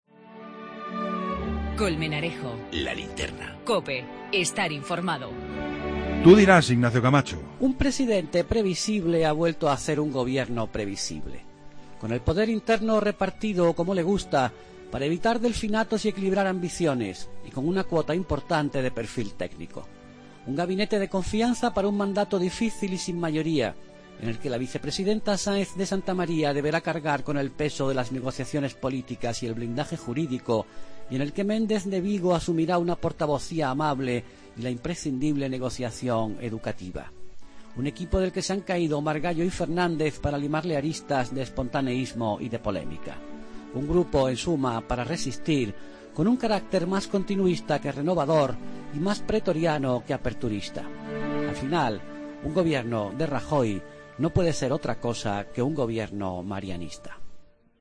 AUDIO: El comentario de Ignacio Camacho en 'La Linterna'